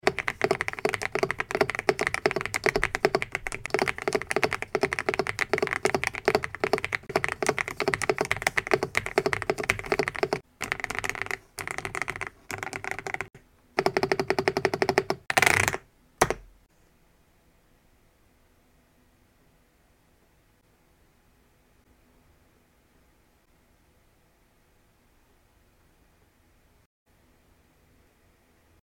The MCHOSE K99 V1 is a beautiful cream colored base with a large blue aluminum logo on the back. Tri-mode connectivity and the perfect sounding creamy thick!